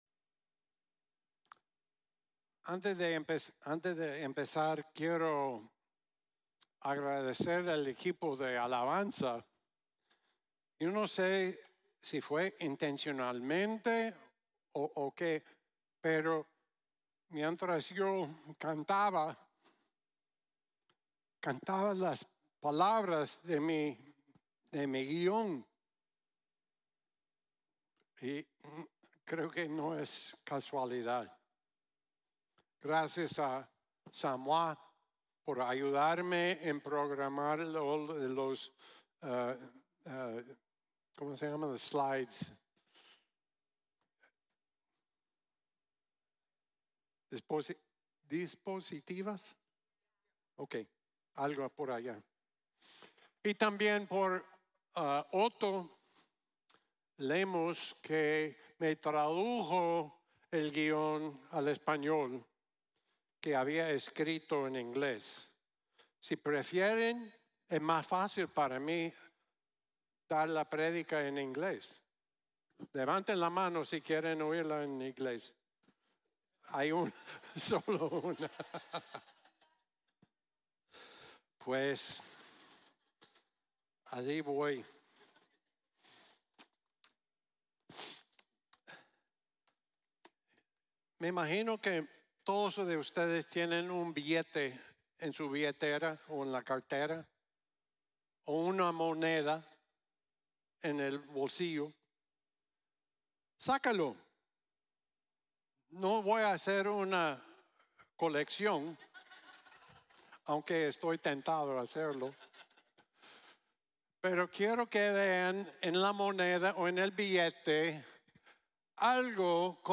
En Dios Confiamo | Sermon | Grace Bible Church